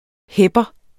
Udtale [ ˈhεbʌ ]